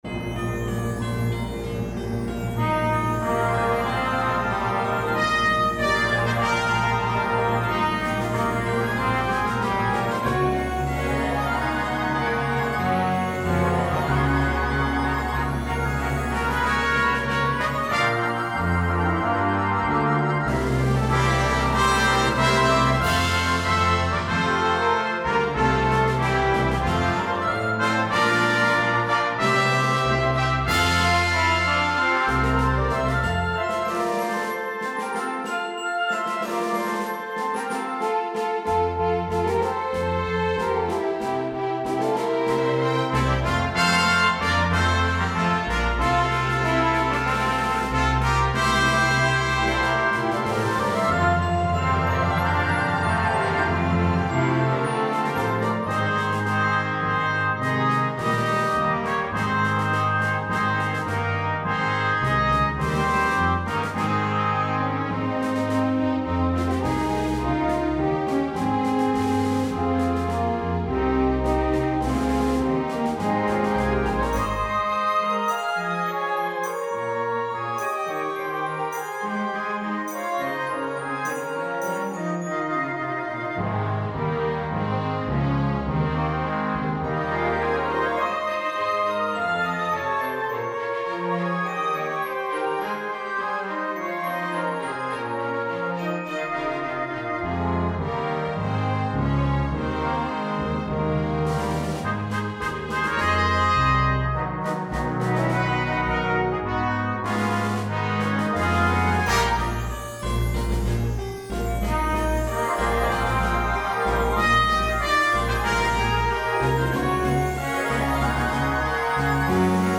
A joyful and festive overture for a service or a program.